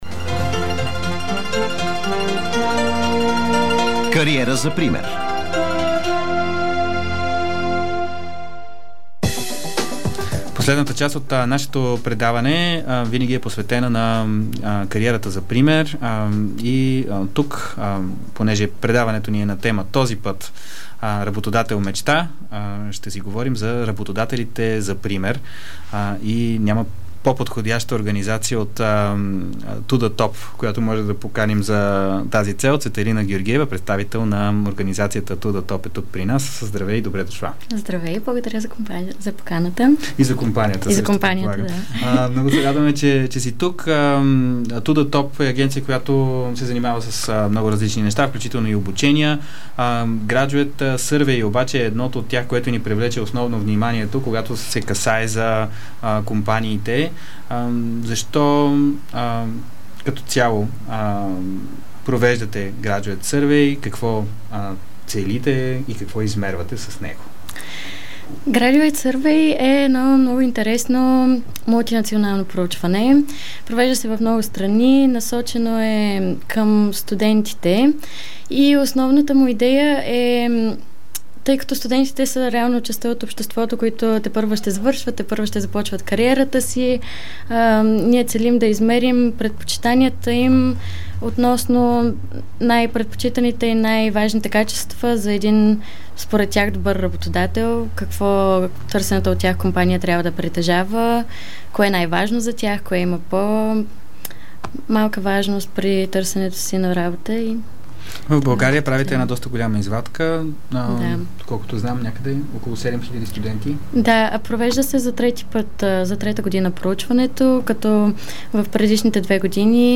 Предаването се излъчва всеки вторник от 19:00 часа на живо по Дарик радио, национален ефир.
*Чуйте цялото интервю в аудио файла.